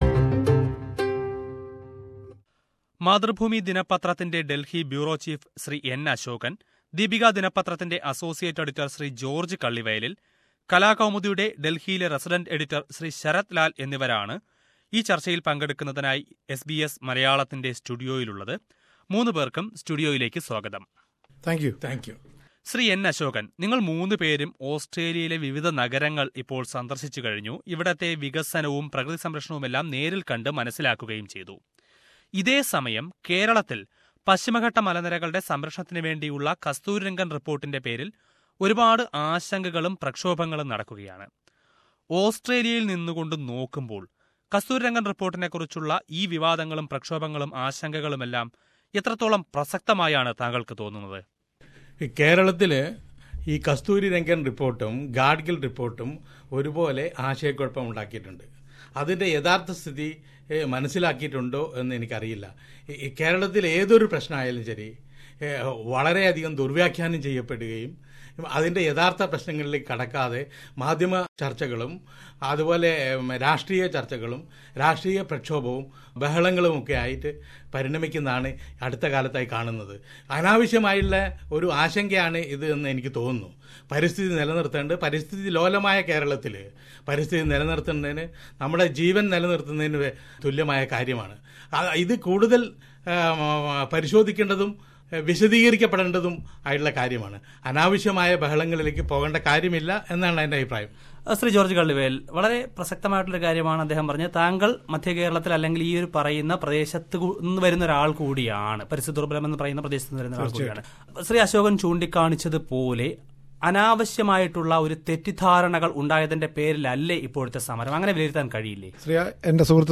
Three prominent Malayalee journalists visited SBS Malayalam's Sydney studio and participated in a panel discussion. They talked about the major issues discussed by the Australian Malayalees in recent times, including the implementation of Kasturi Rangan report in Western Ghats and the proposed Aranmula Airport in Kerala.